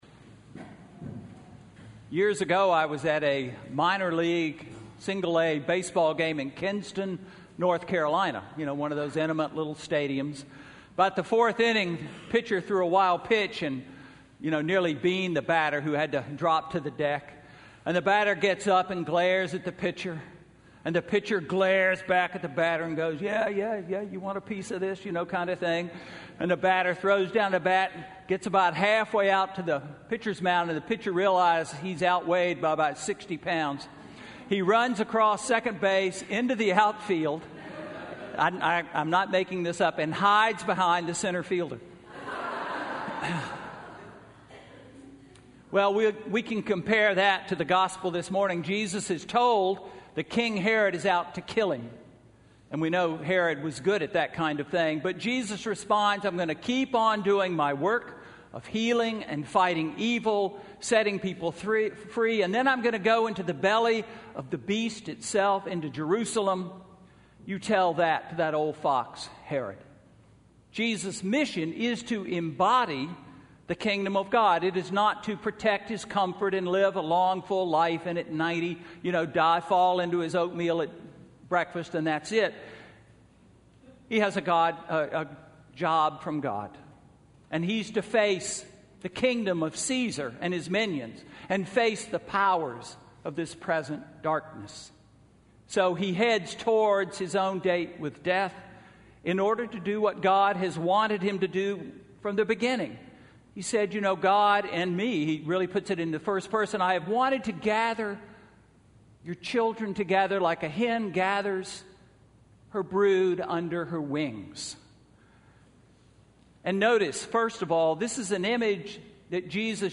Sermon–February 21, 2016